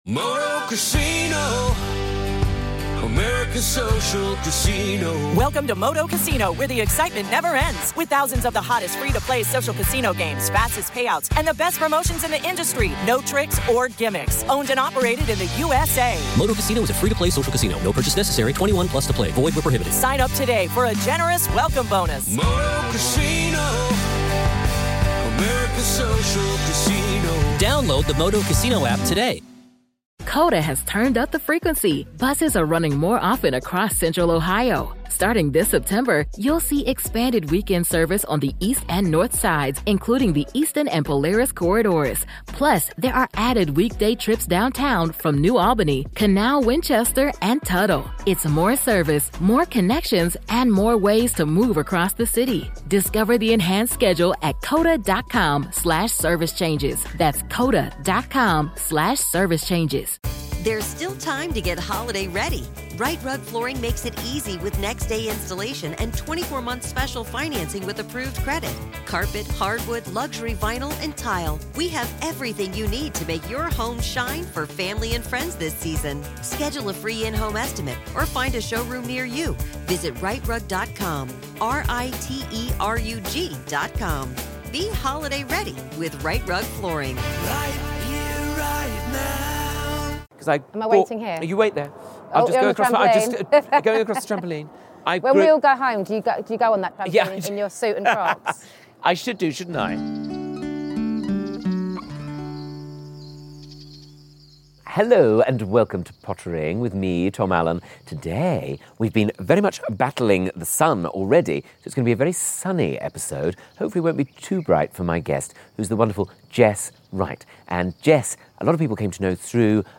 It was my absolute pleasure to welcome the radiant Jess Wright into my garden for a spot of Pottering.
On a delightfully sunny day amongst the hydrangeas, we discussed being a part of the dawn of reality television, dream podcast guests and the lads and their lawnmowers!